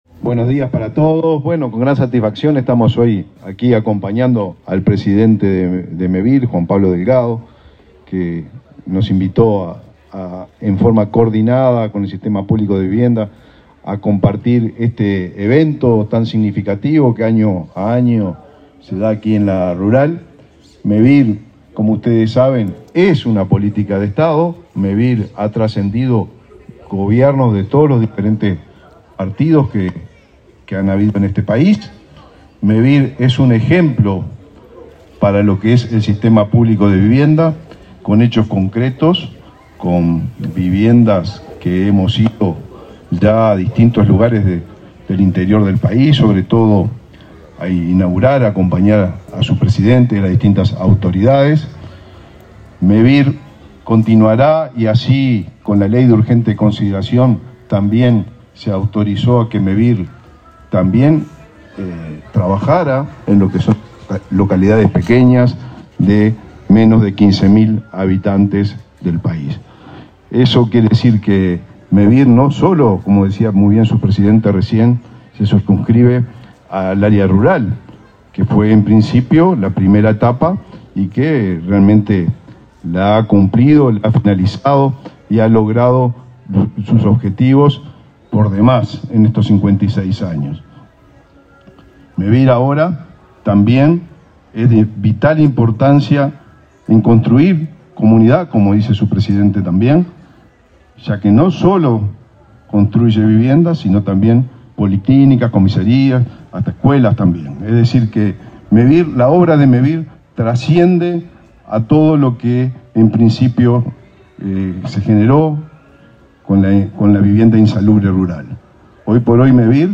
Palabras del ministro de Vivienda, Raúl Lozano
Este lunes 11, el ministro de Vivienda, Raúl Lozano, participó en la inauguración del stand de Mevir y el Sistema Público de Vivienda en la Expo Prado